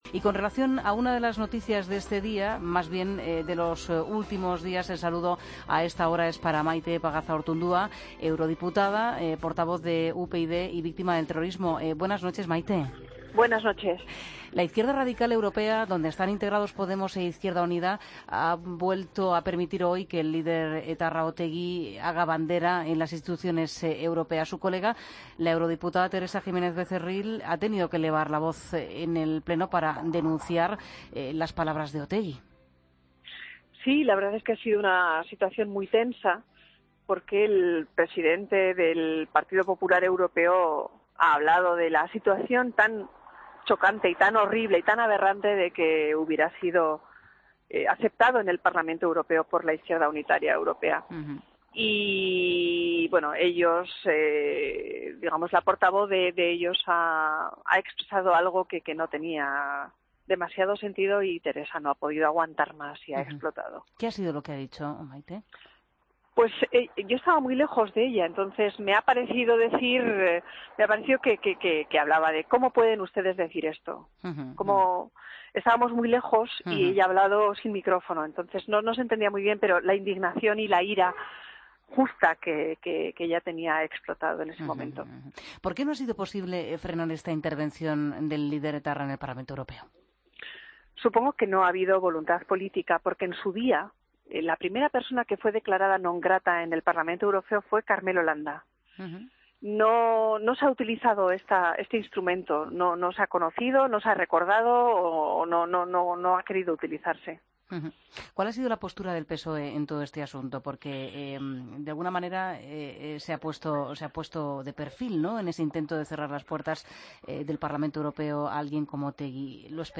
Entrevista a Maite Pagazaurtundúa, eurodiputada de UpyD, en 'La Linterna'